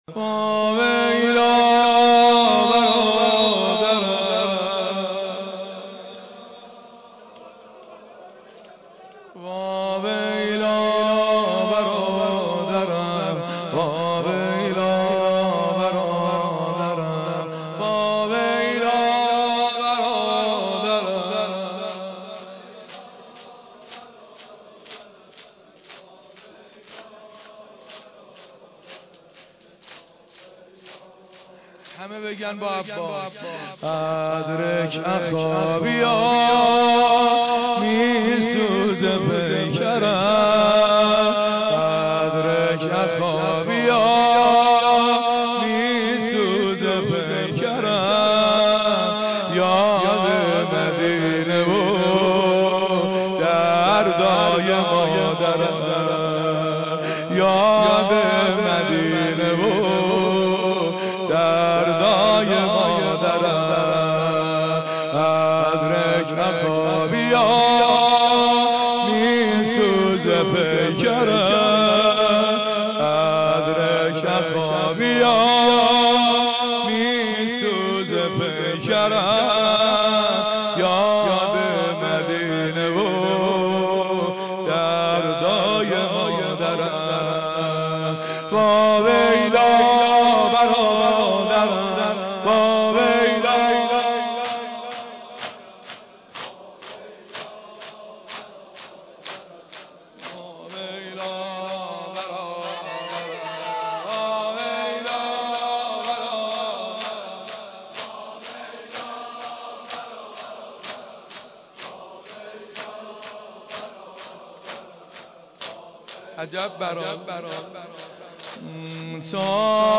زمبنه شب تاسوعا